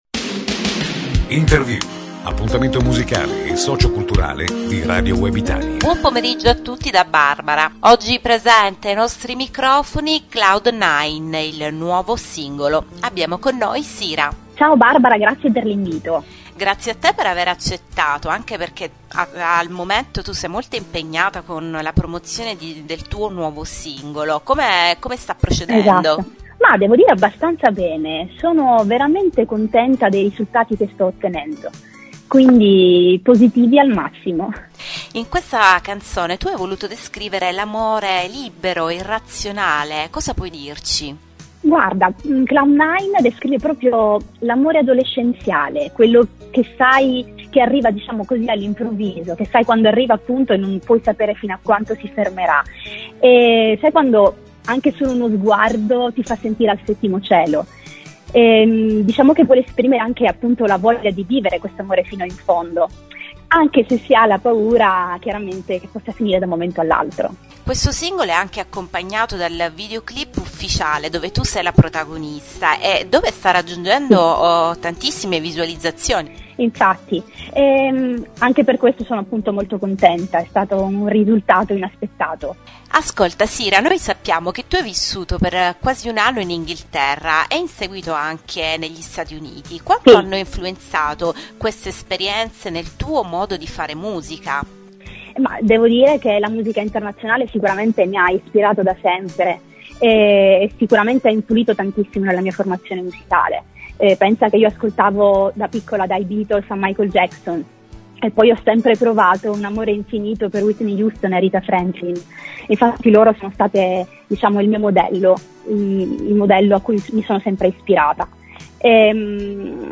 Intervista On Air venerdì 1 febbraio alle ore 18.15